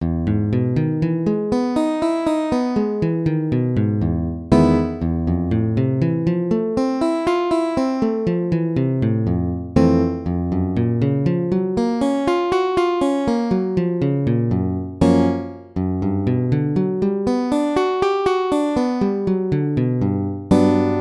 メジャー７アルペジオ
major7 arpeggio
maj7-arpeggio-daily.mp3